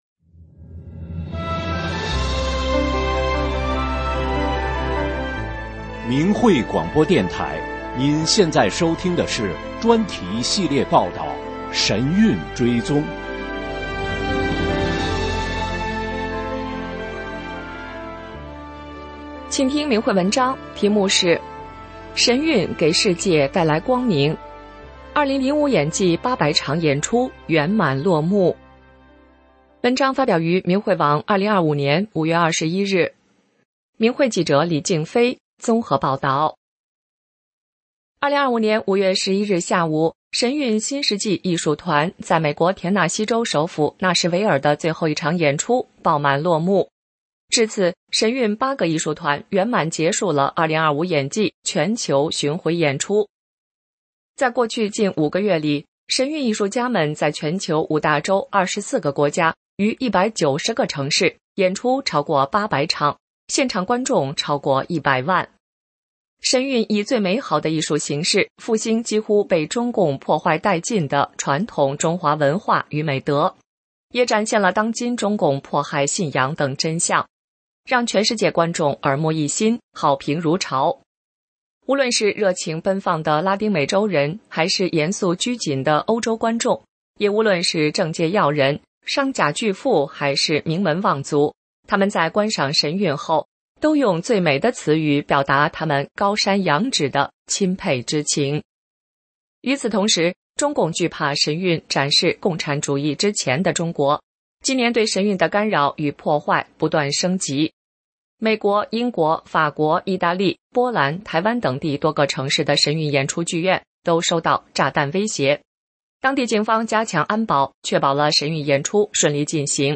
真相广播稿 “神韵给世界带来光明”2025演季800场演出圆满落幕 发表日期： 2025年5月25日 节目长度： 14分57秒 在线收听 下载 4,028 KB 3,505 KB 下载方法 ：按鼠标器右键，在弹出菜单中选择“目标文件保存为…”（Save Target A s…）